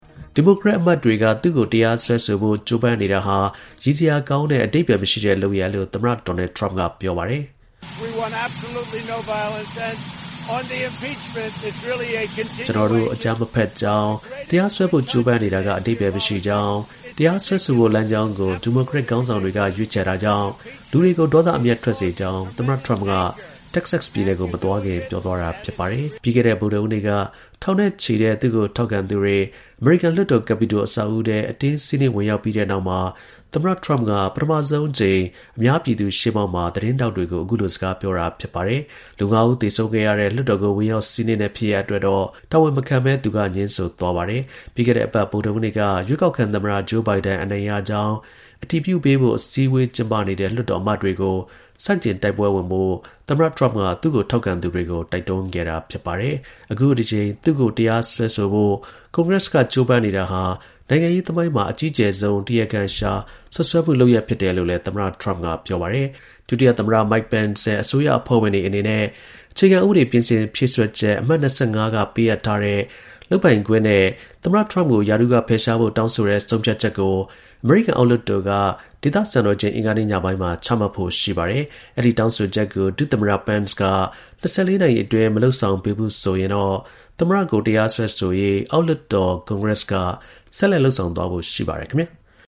သမ္မတ ဒေါ်နယ် ထရမ့် တက္ကဆက်ပြည်နယ် မသွားခင် သတင်းထောက်တွေကို ဖြေကြား (ဇန်နဝါရီလ ၁၂ ရက် ၂၀၂၁)